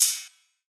Closed Hats
DDW Hat 3.wav